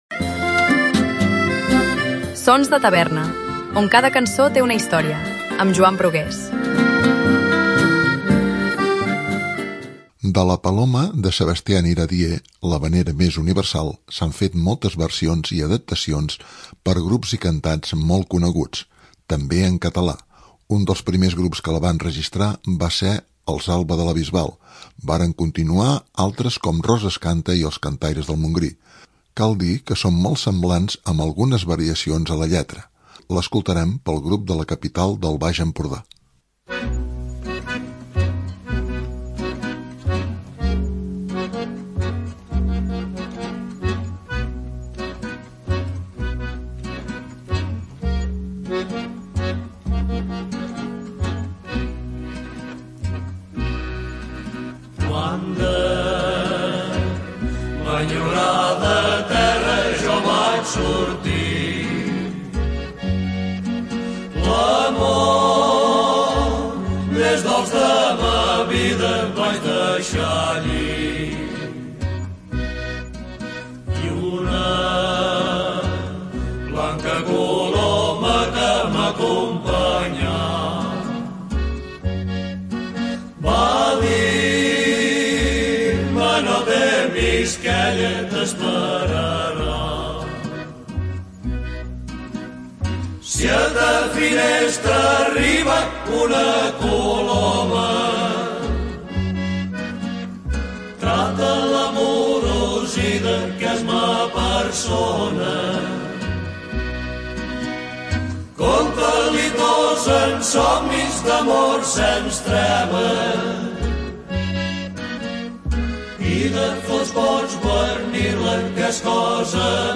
l’havanera més universal
L’escoltarem pel grup de la capital del Baix Empordà.